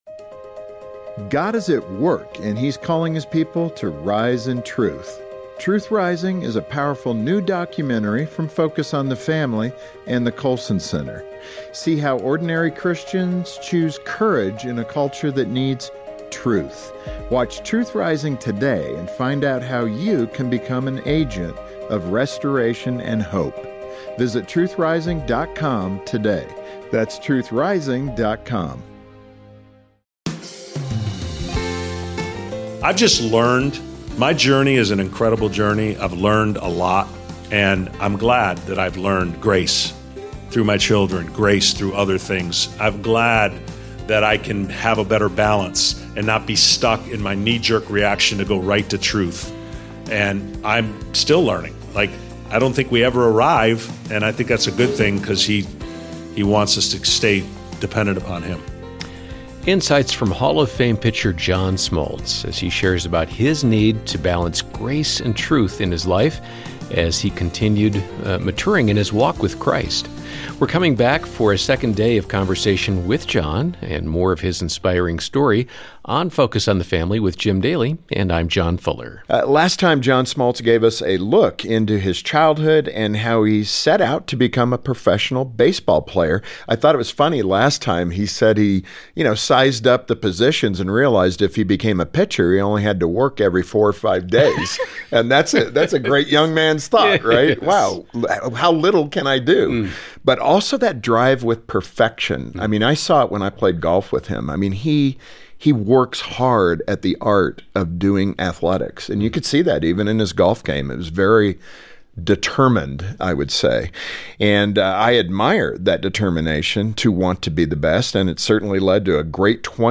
John Smoltz is a World Series champion, former Cy Young award-winner and hall of fame pitcher. He shares his inspiring story about the twists and turns in his career, finding faith in Christ, and rebuilding his life after experiencing hardship. He reveals how God taught him to surrender control and trust Him, while growing in grace in this one-on-one conversation with Jim Daly.